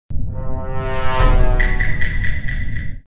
Download Among Us sound effect for free.